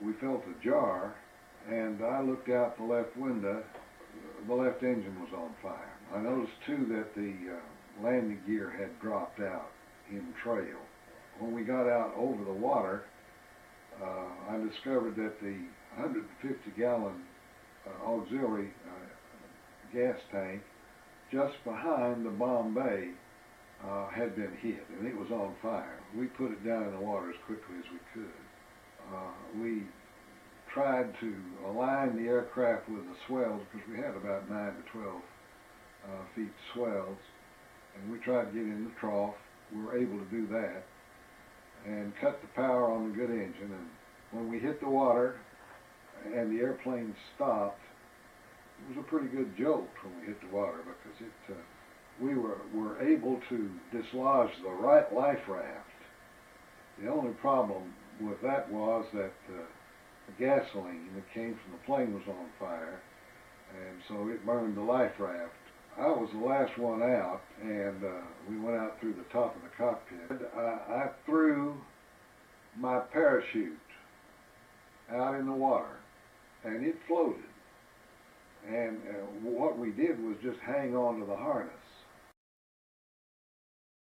and interview with his father.